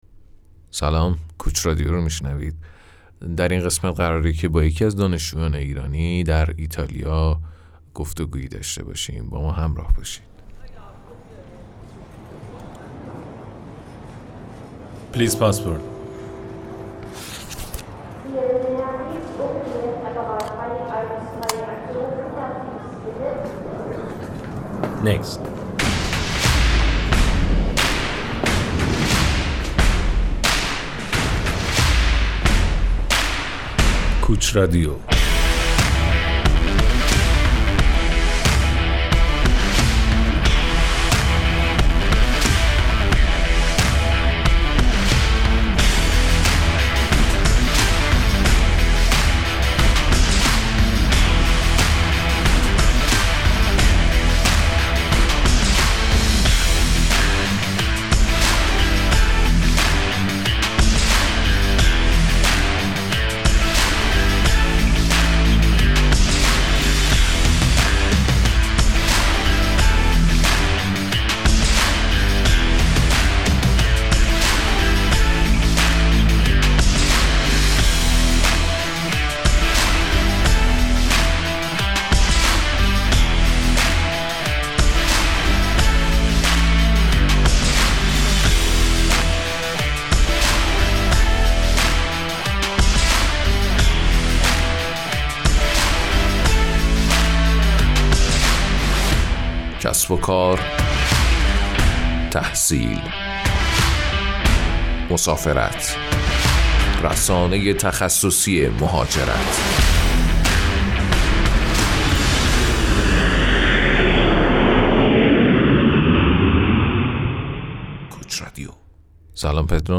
مصاحبه-با-دانشجو-ایتایا.mp3